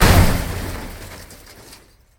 hammer.ogg